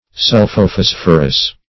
Search Result for " sulphophosphorous" : The Collaborative International Dictionary of English v.0.48: Sulphophosphorous \Sul`pho*phos"phor*ous\, a. (Chem.)